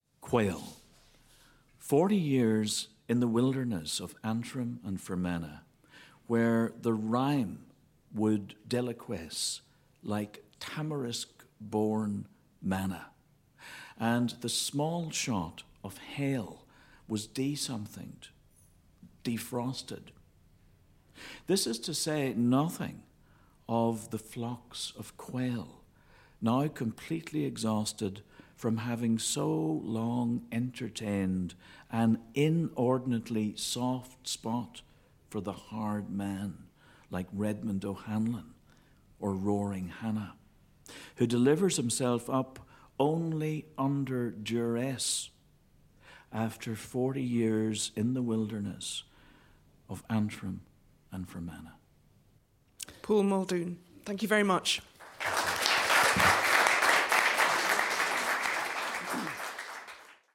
Poet Paul Muldoon reading his poem Quail at BBCTheForum in Belfast